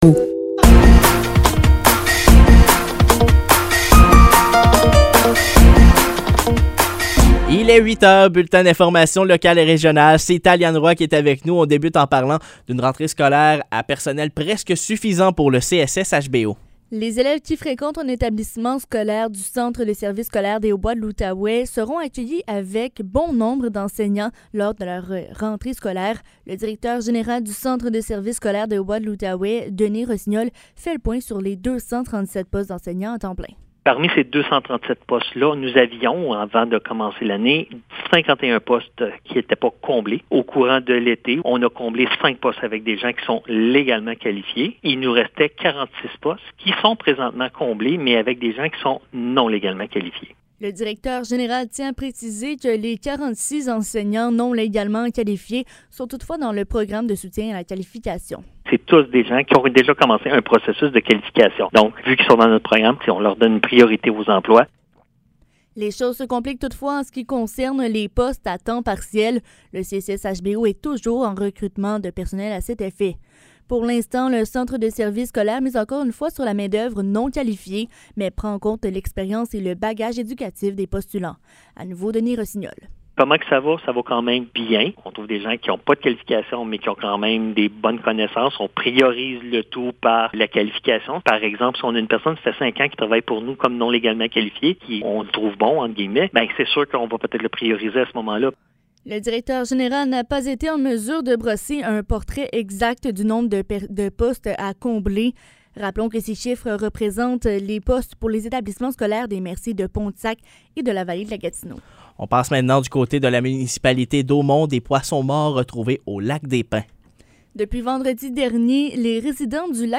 Nouvelles locales - 23 août 2023 - 8 h